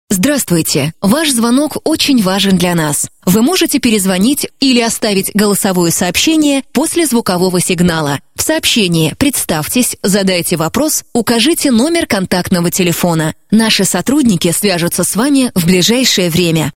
На автоответчик [10]